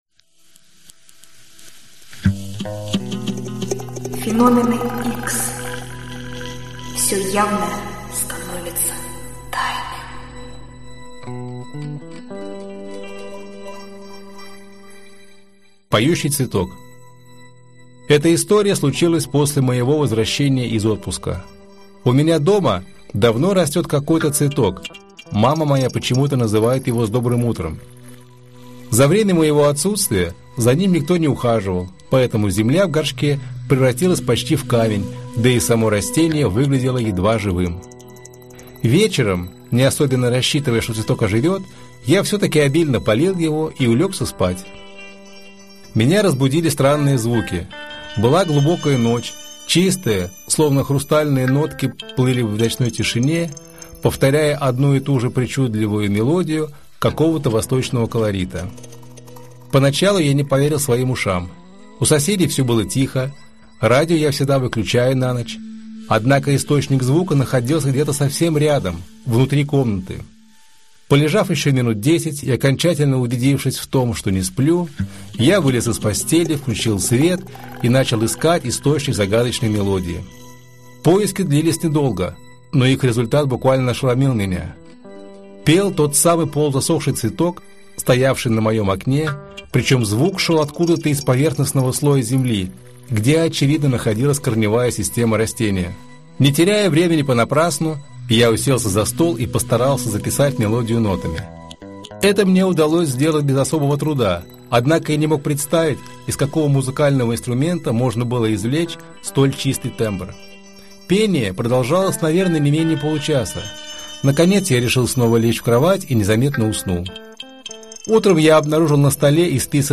Аудиокнига Невыдуманные истории. Невероятное и необъяснимое в рассказах очевидцев | Библиотека аудиокниг